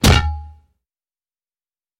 Вылет снаряда из ствола